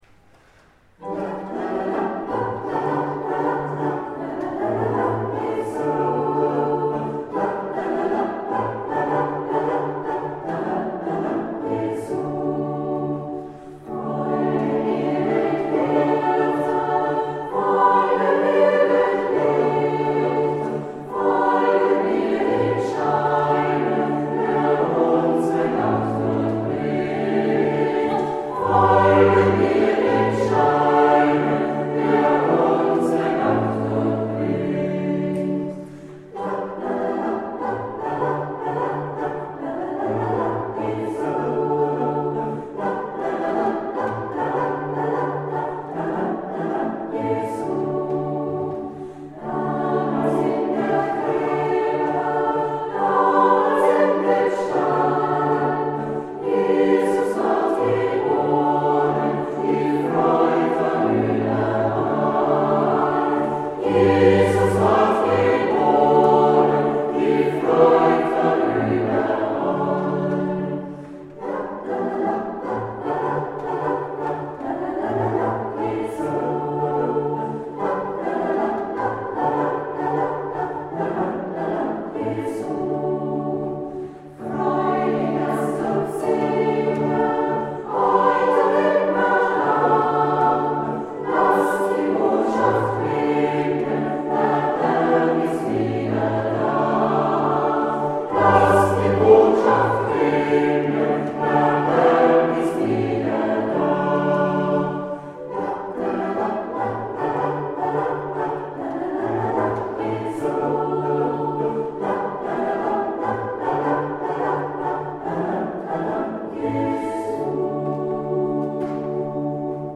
Wir singen vor der Christmette traditionelle Weihnachtslieder - Aufnahmen von der Generalprobe